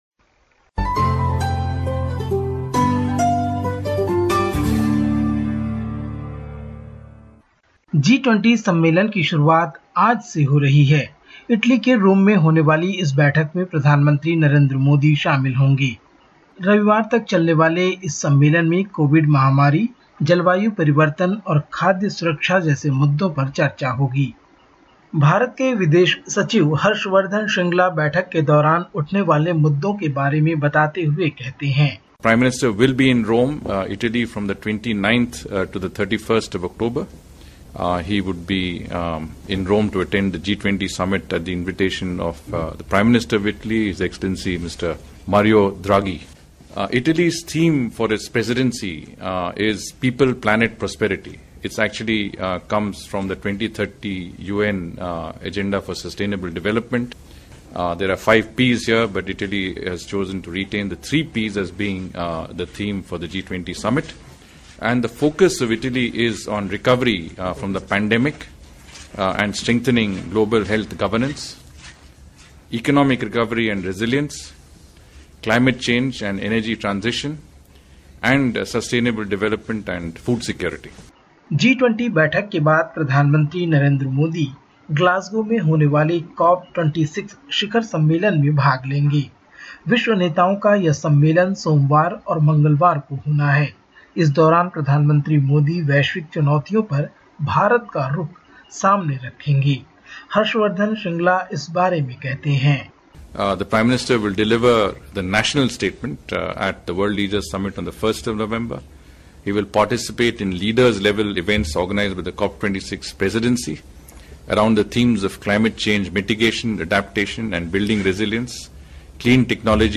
India report in Hindi: Indian Prime Minister Narendra Modi leaves for Rome to attend G20 summit